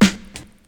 • 00s Hip-Hop Snare One Shot G Key 424.wav
Royality free steel snare drum sample tuned to the G note. Loudest frequency: 1297Hz
00s-hip-hop-snare-one-shot-g-key-424-mCG.wav